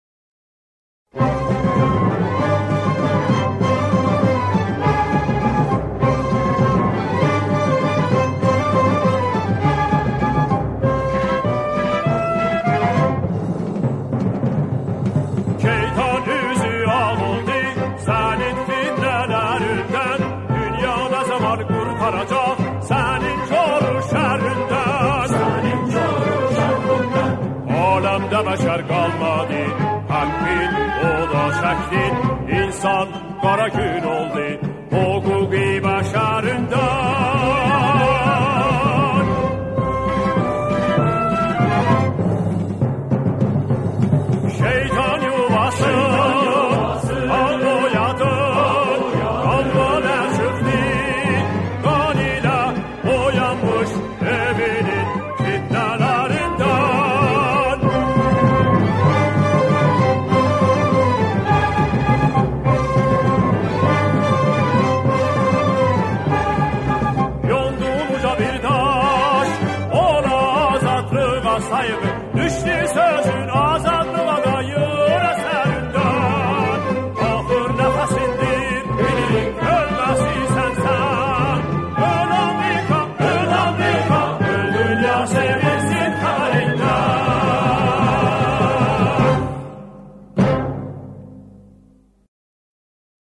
به گویش آذری سروده است.